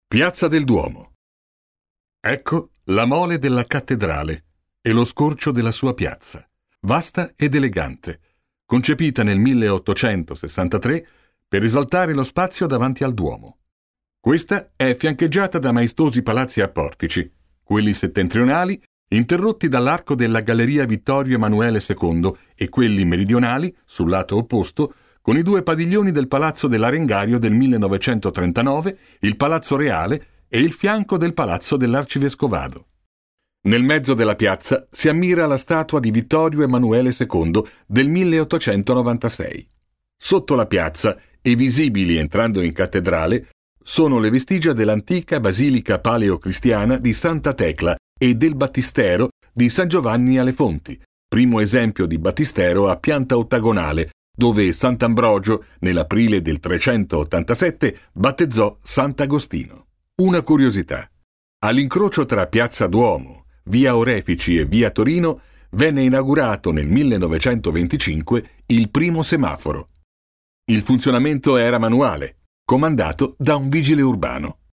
Speaker italiano, esperienza trentennale, voce adattabile a qualsiasi estensione vocale, imitatore, caratterista...
Sprechprobe: Sonstiges (Muttersprache):